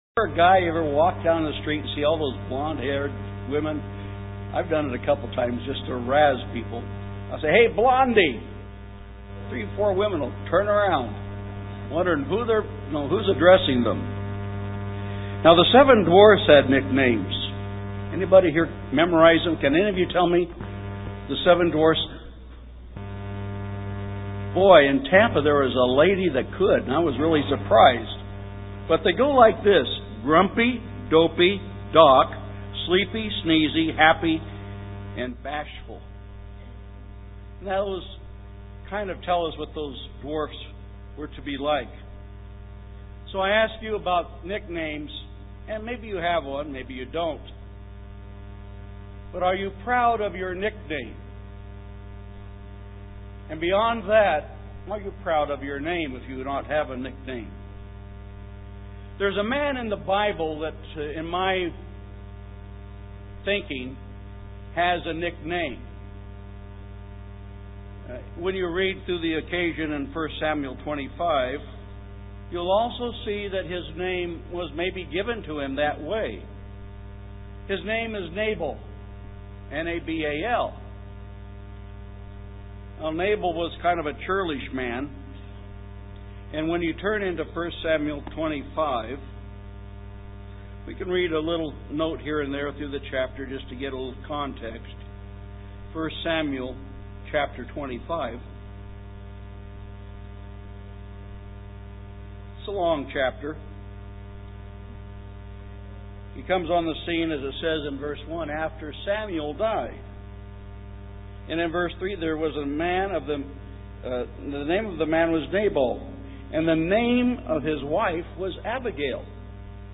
This sermon was given at the Jamaica 2013 Feast site.